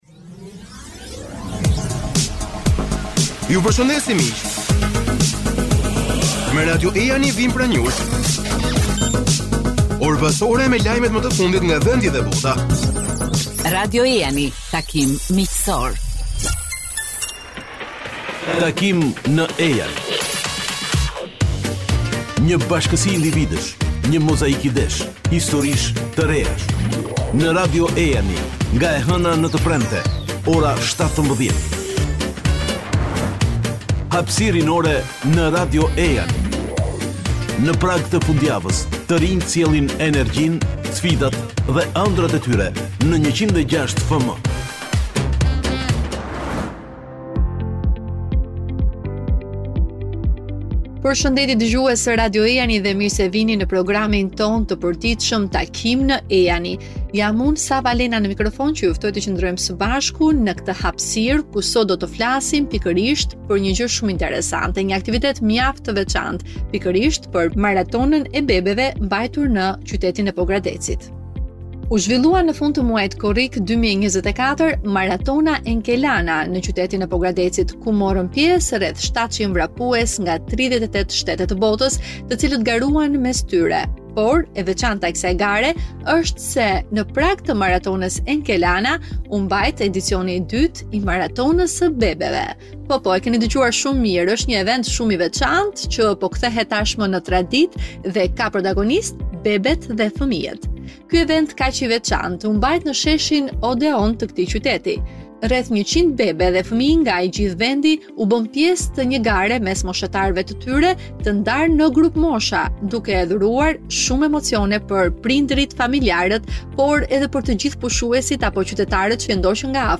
Në intervistë për Radio Ejani tregon detaje rreth kësaj nisme, nënkryetarja e Bashkisë së Pogradecit Entela Gusho.